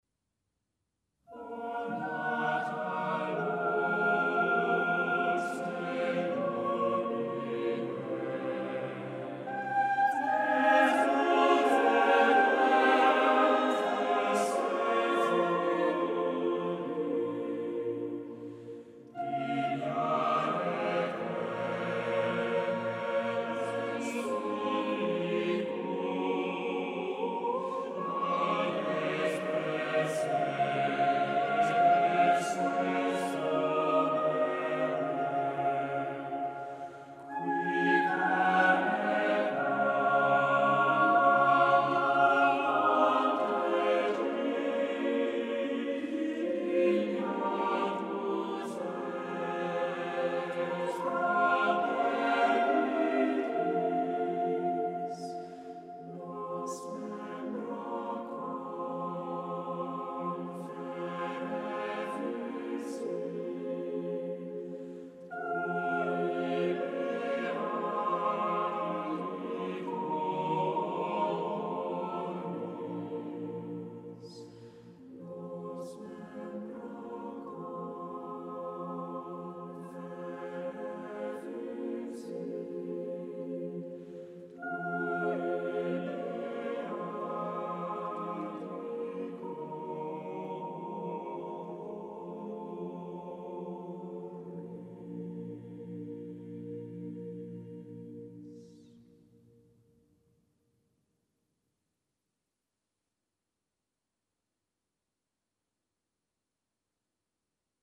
Listen to the Cambridge Singers perform "O Nata Lux" by Thomas Tallis (c. 1505-1585).
O Nata Lux de Lumine for 5 voices.mp3